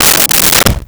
Dresser Drawer Opened 04
Dresser Drawer Opened 04.wav